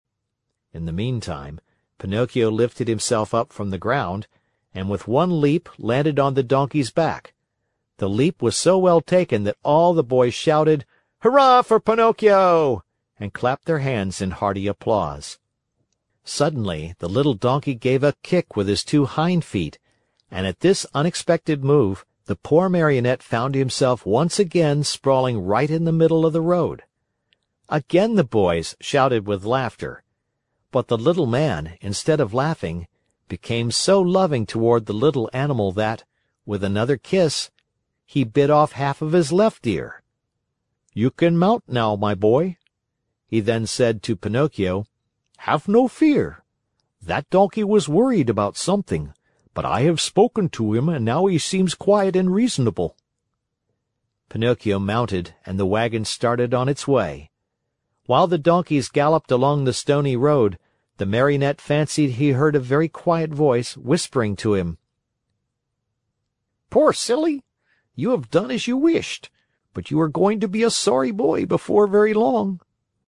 在线英语听力室木偶奇遇记 第114期:在玩具国享乐(5)的听力文件下载,《木偶奇遇记》是双语童话故事的有声读物，包含中英字幕以及英语听力MP3,是听故事学英语的极好素材。